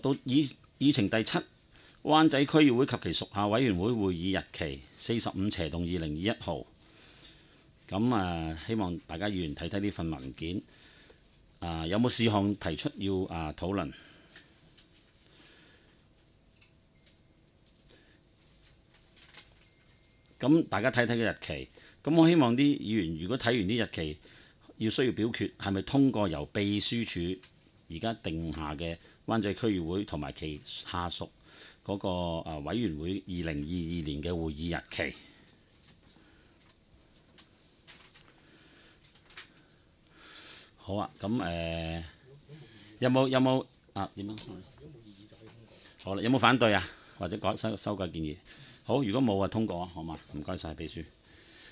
区议会大会的录音记录
湾仔区议会第十四次会议
湾仔民政事务处区议会会议室